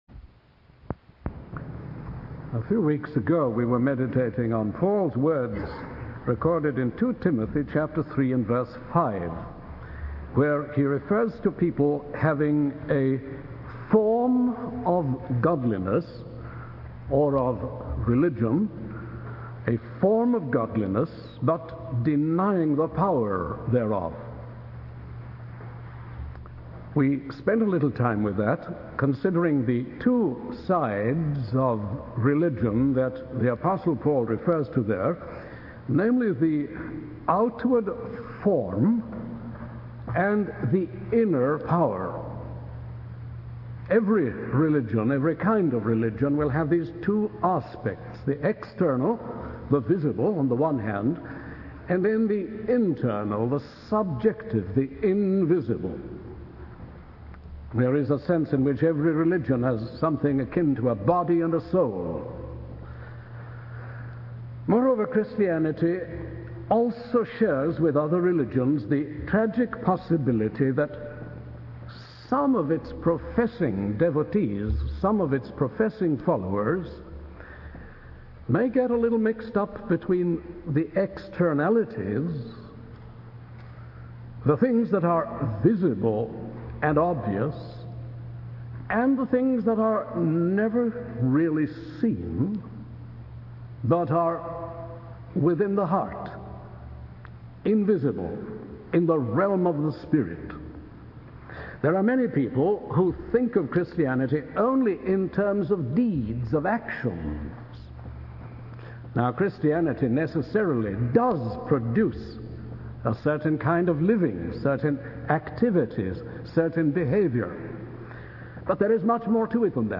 In this sermon, the speaker discusses the ninefold fruit of the Spirit as described by Paul.